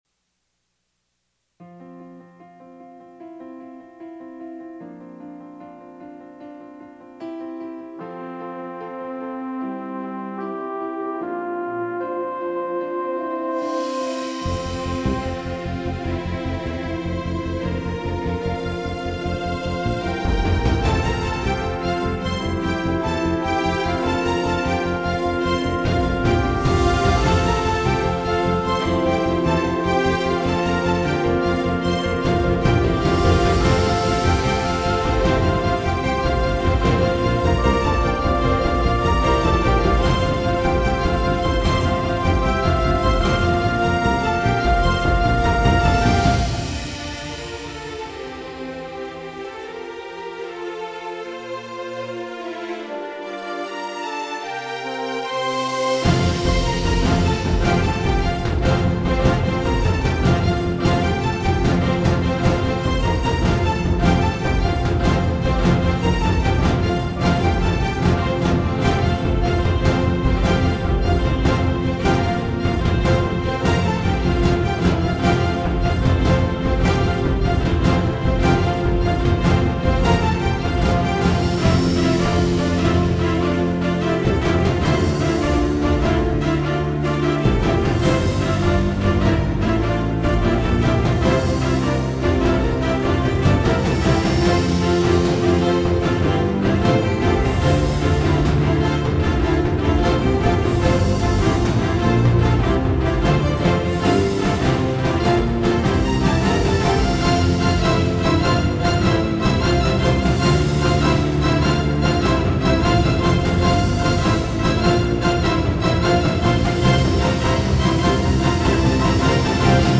LAMRAG, Ray of Light: 2nd Annual Benefit Concert
popular classic rock songs, both English and Tagalog